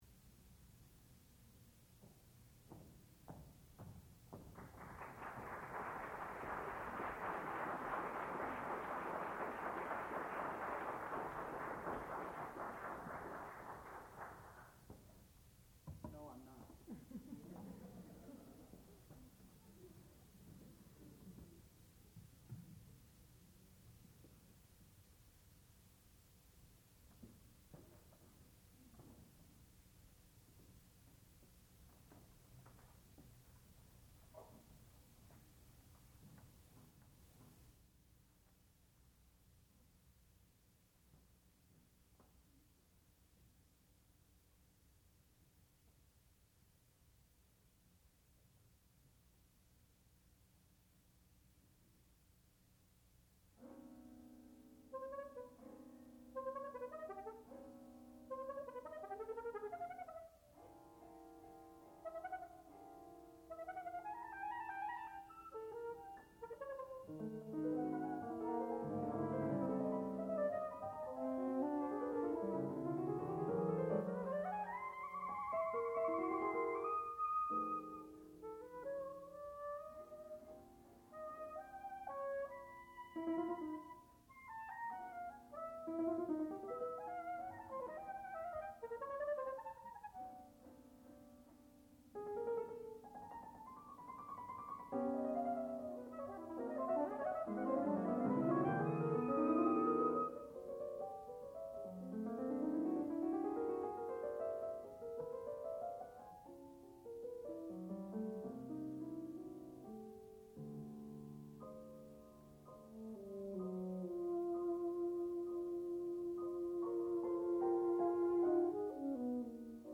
sound recording-musical
classical music
trombone
flute
percussion